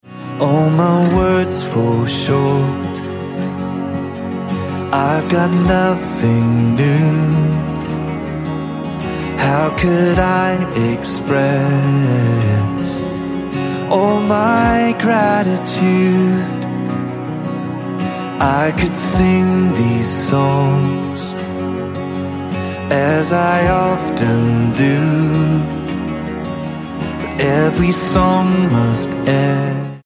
Gb